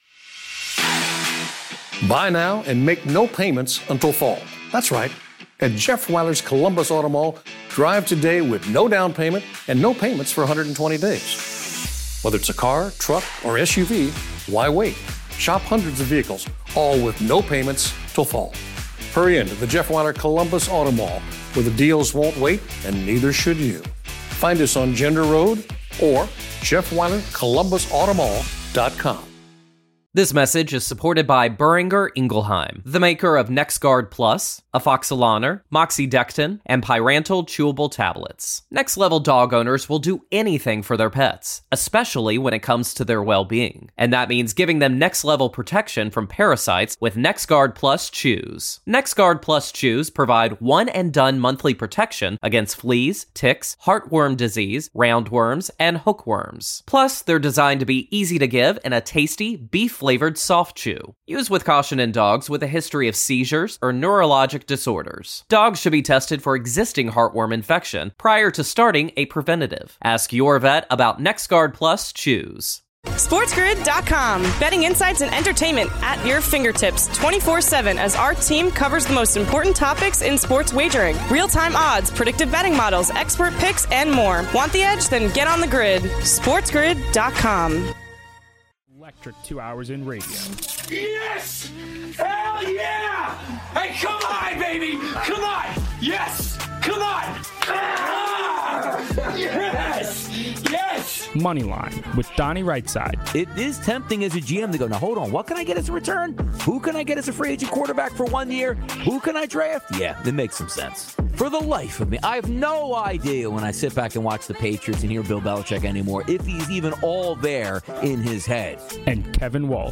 Then - NFL week 11 preview. The guys look at the early lines and where they are leaning early on. Finally - a full MNF preview of the Commanders vs Eagles game. All this - your calls - and more.